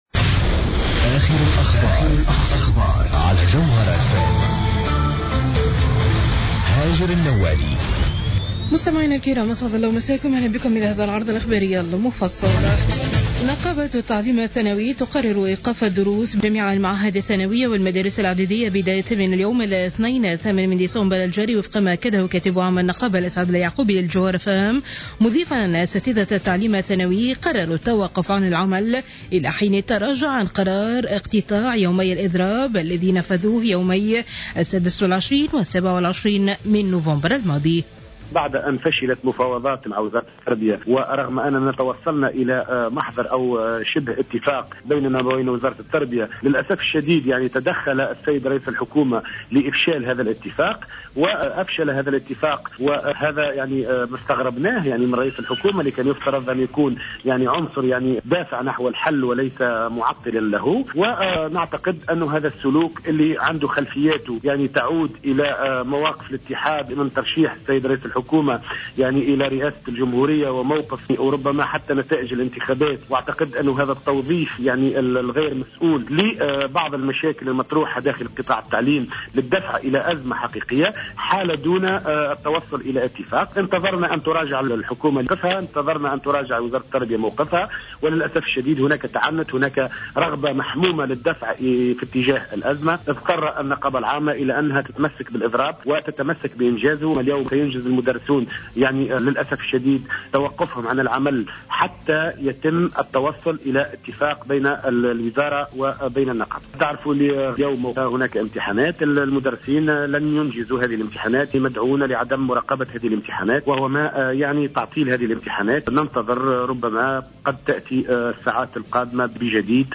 نشرة أخبار منتصف الليل ليوم 08-12-14